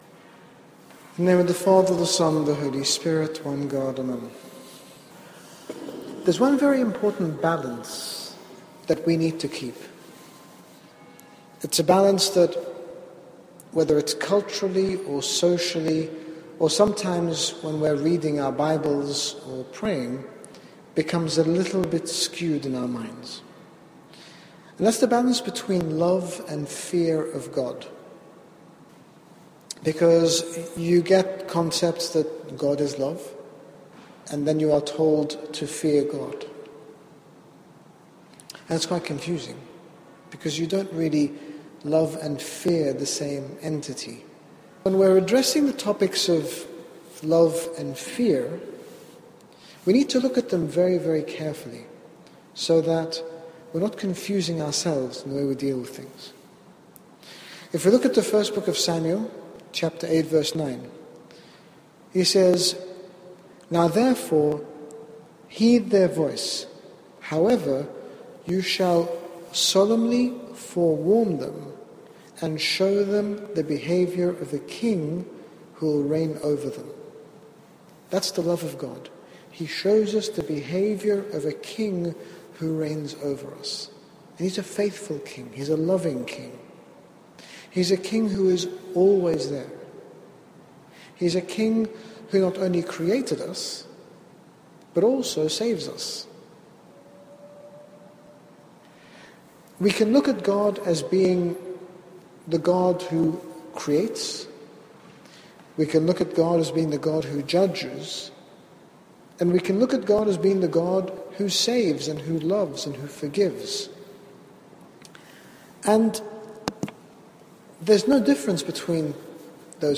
As we use social media to become more connected there is a danger that we actually become more isolated and disconnected from the world around us, and we can lose sight of the bigger picture. During this talk at NCCYC in San Francisco, His Grace Bishop Angaelos speaks about the various challenges that social media poses in our lives, highlighting positive ways in which it can be used to edify others, while also warning of the damaging ways it can be used to cause harm to ourselves and others. Download Audio Read more about Living one Life Series Part 1 - The influence of social media